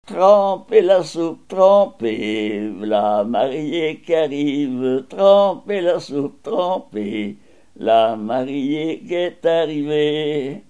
circonstance : fiançaille, noce
Genre brève
Pièce musicale inédite